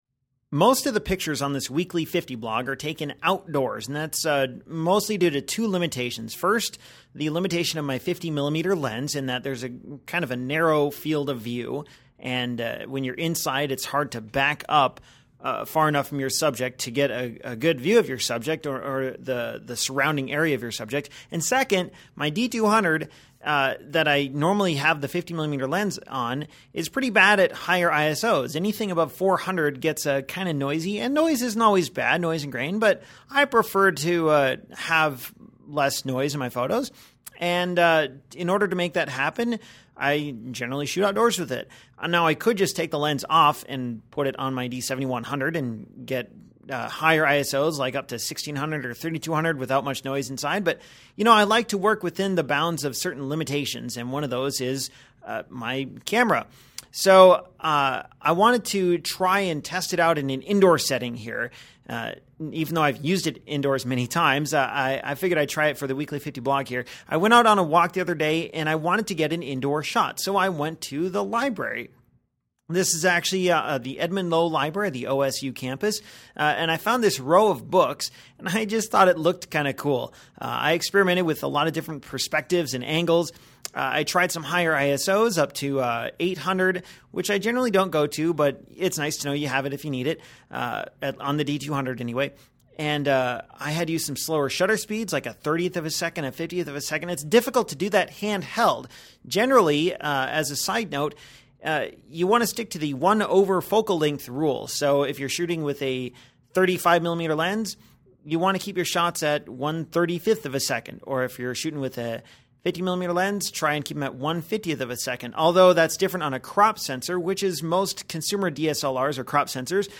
In case you’re wondering, here’s the photo taken by a former student that I mention in the audio commentary.